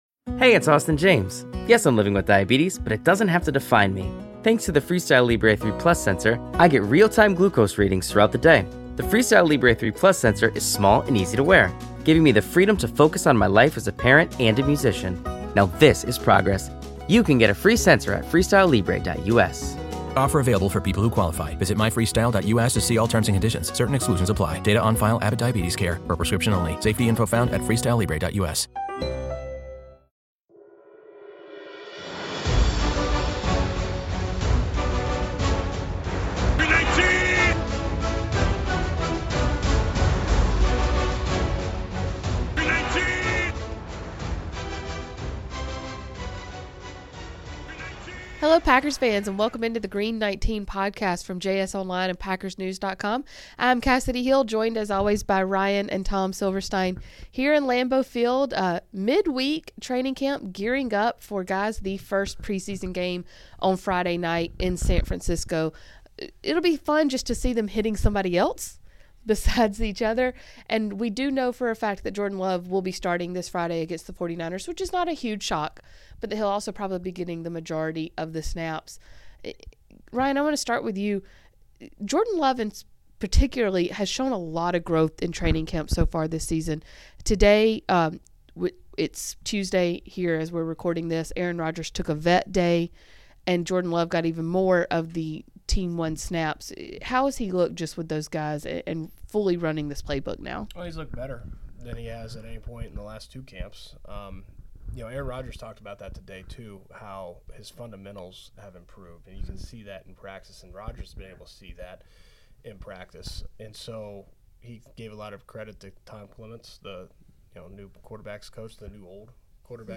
*This episode has been updated to address audio level issues